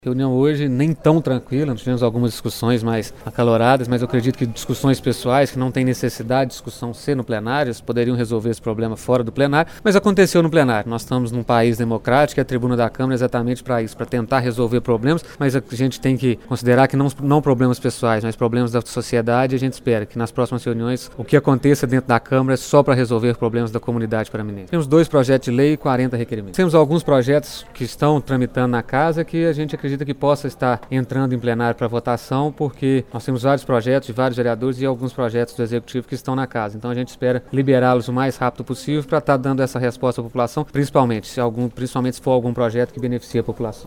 A reunião Ordinária da Câmara Municipal de Pará de Minas foi realizada na noite desta segunda-feira, 8 de outubro.
O presidente da Casa, Marcus Vinícius Rios Faria (MDB), afirmou que problemas pessoais acabaram sendo tratados em plenário. Acrescentou que alguns projetos estão tramitando e em breve serão colocados em votação: